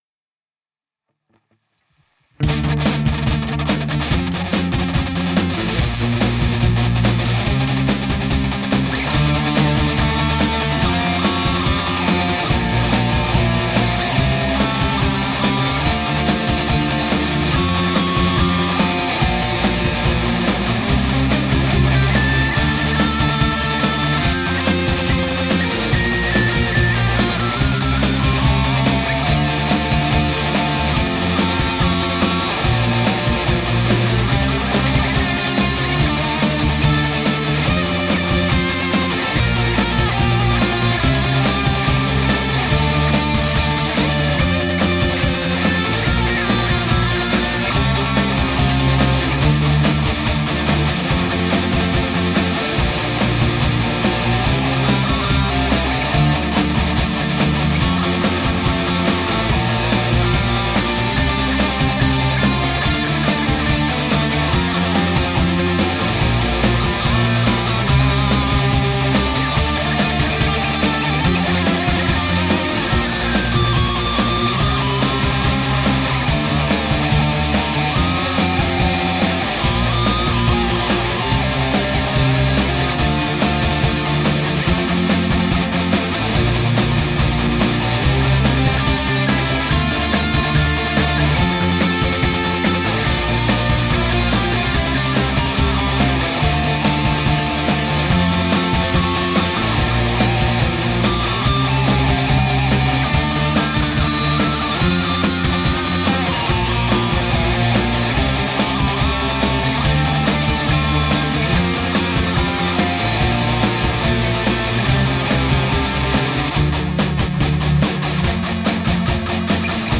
E-Git-Recording - Tipps/Alternativen zum Mix u. Setup (z.Zt. UMC22 + Studio-One free)
Hört sich auf jeden Fall schonmal klarer an.
Die Bassgitarre ist auch ein Effekt des RP355.
Eins mal vorneweg: Einiges klingt jetzt schlechter als vorher, das soll jedoch nur zur Veranschaulichung dienen wovon ich spreche.
-Gitarren: (Ich bezieh mich nur auf die Rhytmusgitarren, Sologitarre hab ich so gut wie möglich ignoriert, kann ih nicht gezielt einzeln bearbeiten, muss in den Einzelspuren gemacht werden) Boost bei 150Hz (klangen etwas dünn, die Palmmutes) Präsenzen um 2kHz angehoben. Und um die 8kHz satte 26dB! geboostet.